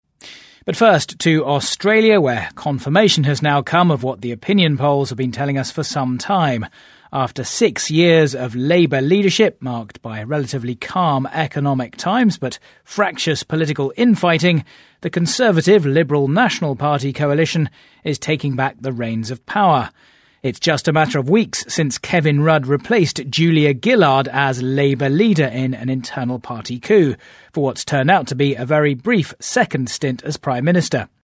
【英音模仿秀】阿博特赢得澳大利亚大选 听力文件下载—在线英语听力室